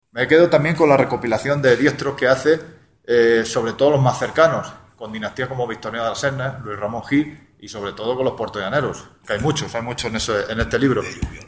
La presentación de este riguroso trabajo tenía lugar en el Museo Municipal de Puertollano